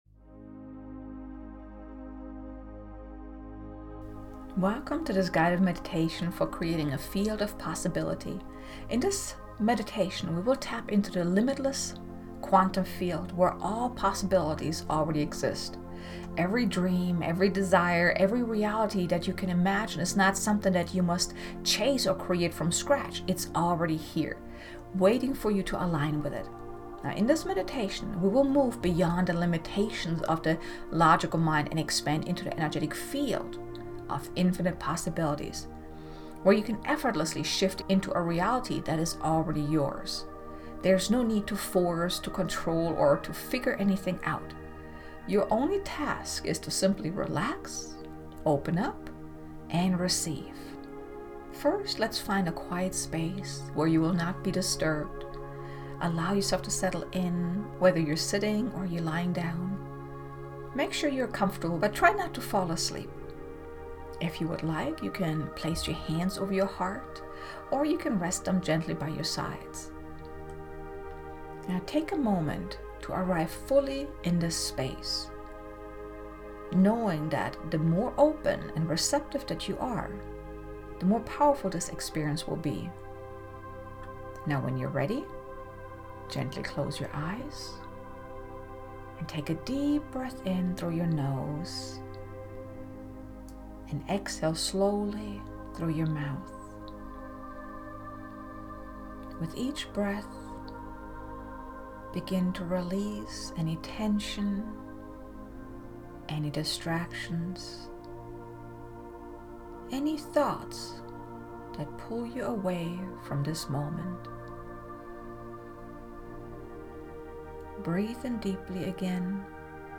Guided Meditation: Creating a Field of Possibility
Guided-Meditation-Creating-a-Field-of-Possibility-2.mp3